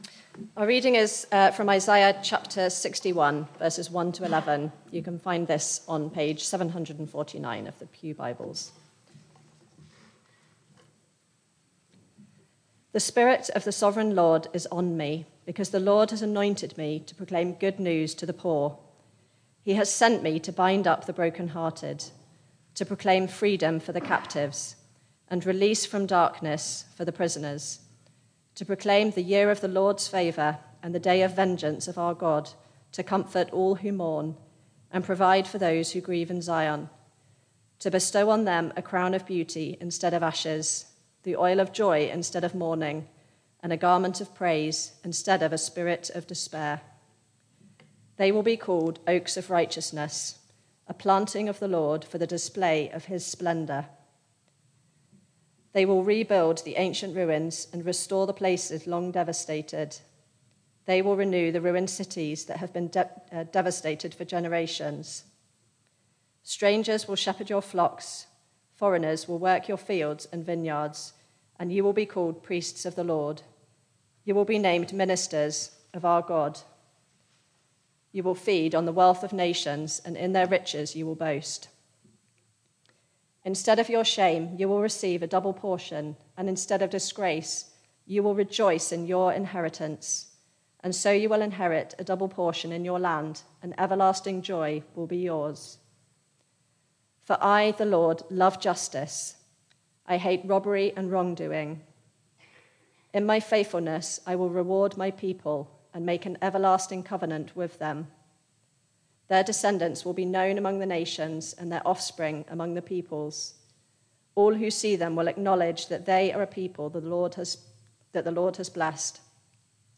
Barkham Morning Service
Reading and sermon